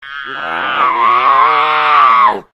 sounds / monsters / cat / c2_die_2.ogg
c2_die_2.ogg